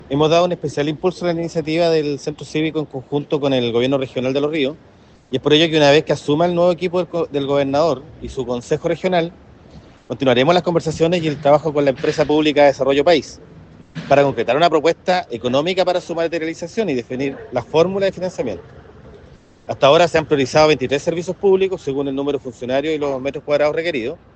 Por su parte, el delegado Presidencial, Jorge Alvial, afirmó que hasta ahora son 23 los servicios públicos que han mostrado interés real de ser parte del Centro Cívico.